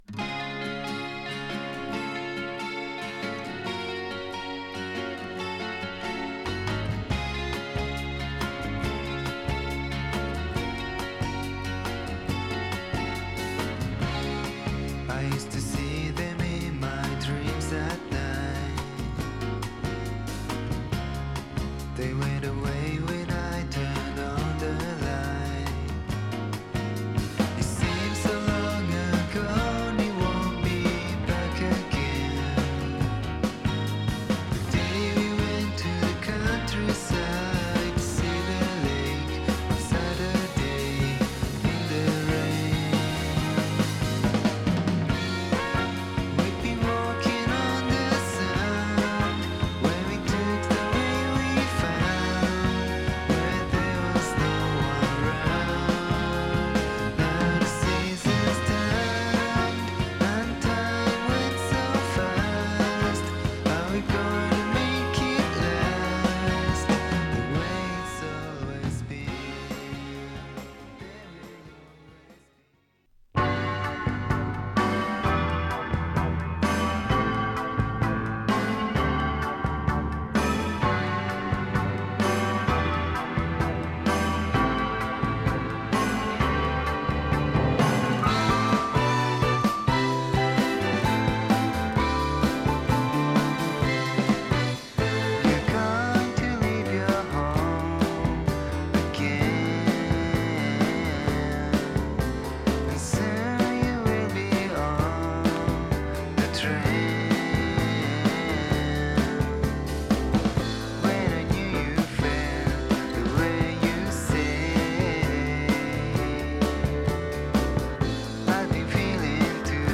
木漏れ日のネオアコ、２０年前に録音してありながらお蔵入りとなっていた本作品が遂に日の目を浴びます。
優しく撫でるようなギターの音色、ジェントルで落ち着いたボーカルは何処と無く哀愁すら感じさせる。